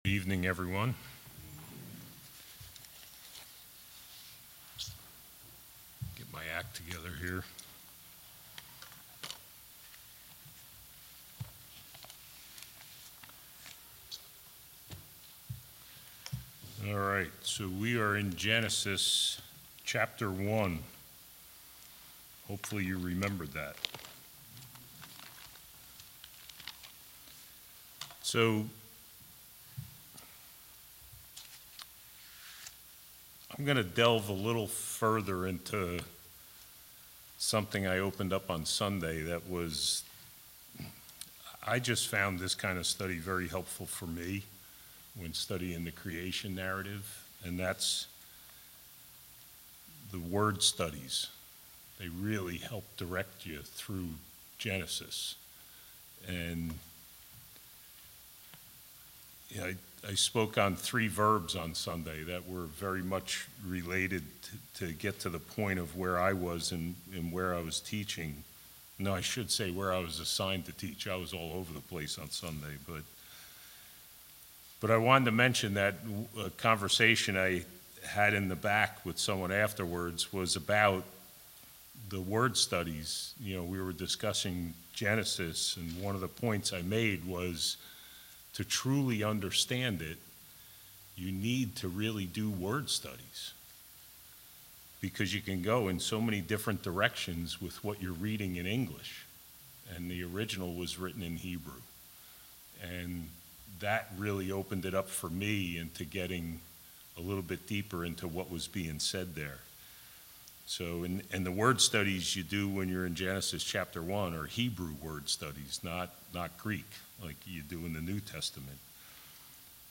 All Sermons Genesis 1:14-19